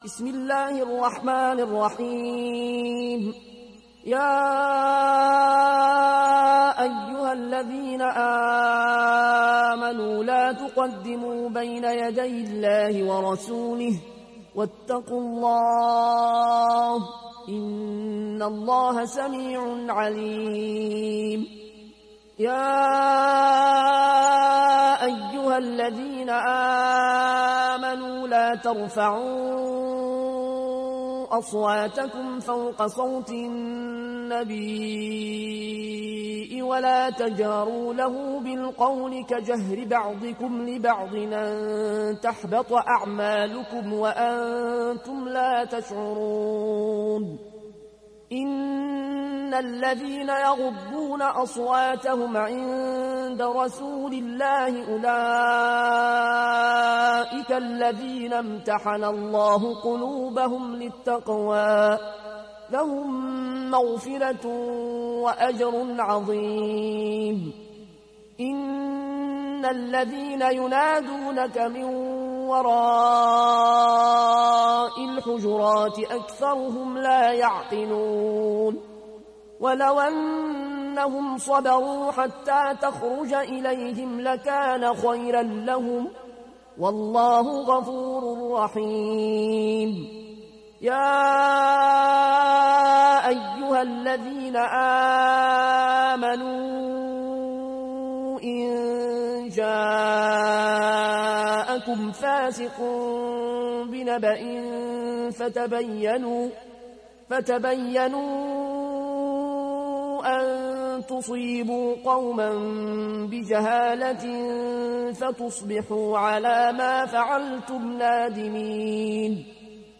সূরা আল-হুজুরাত ডাউনলোড mp3 Al Ayoune Al Koshi উপন্যাস Warsh থেকে Nafi, ডাউনলোড করুন এবং কুরআন শুনুন mp3 সম্পূর্ণ সরাসরি লিঙ্ক